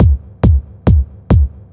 Kick 138-BPM.wav